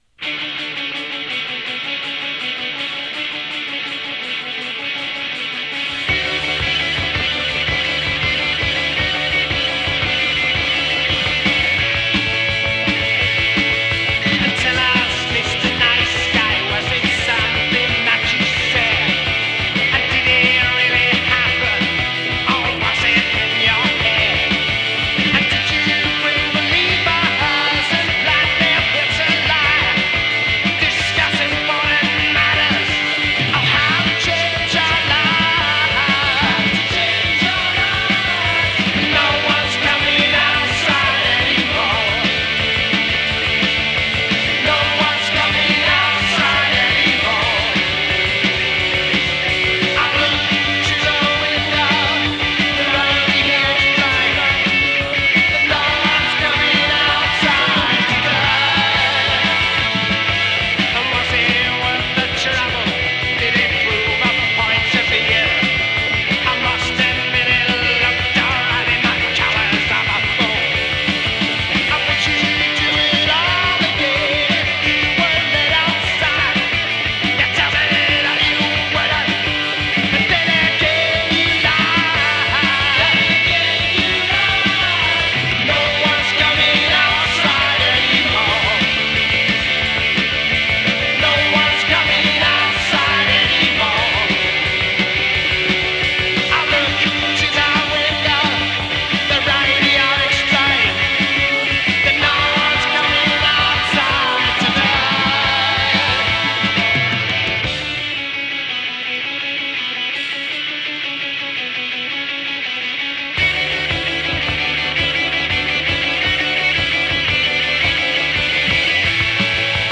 疾走 初期パンク